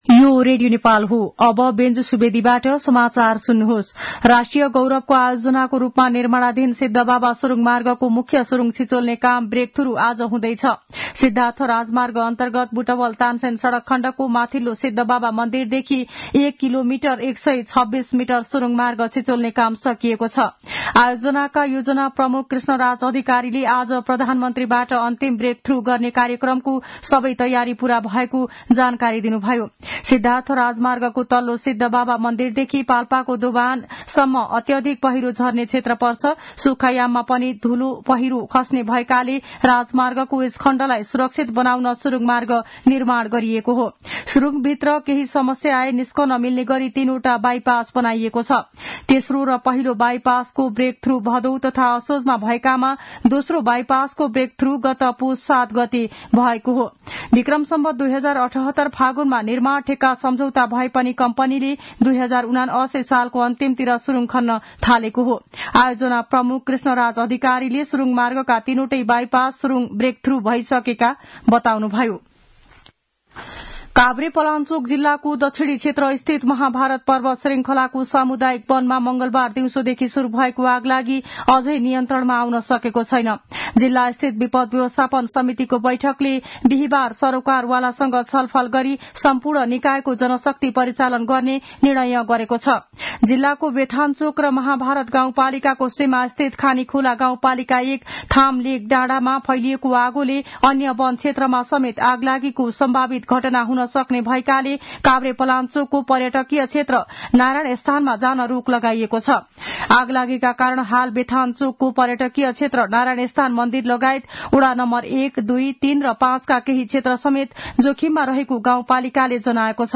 मध्यान्ह १२ बजेको नेपाली समाचार : १२ माघ , २०८१
12-am-news-1-12.mp3